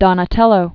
(dŏnə-tĕlō, dōnä-tĕllō) Originally Donato di Niccolò. 1386?-1466.